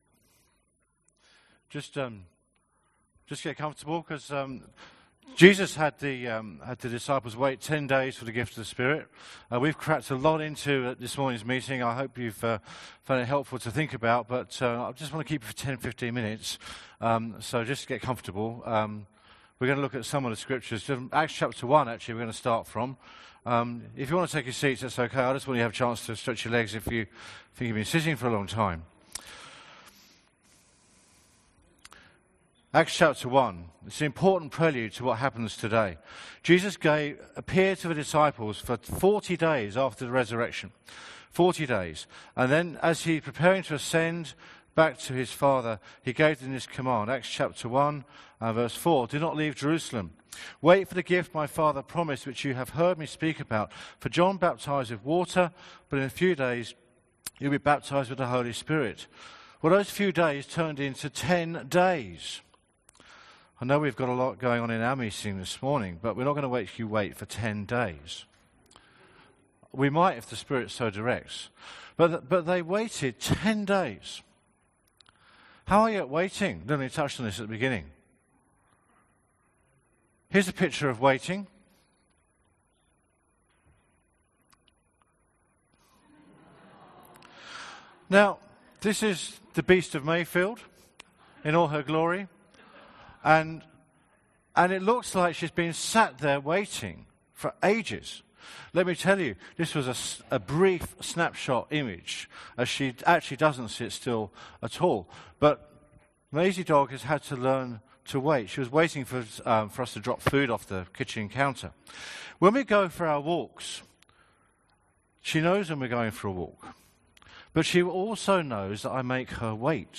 Sermon from the 10AM meeting at Newcastle Worship & Community Centre of The Salvation Army. The sermon related to Acts 2:1-11.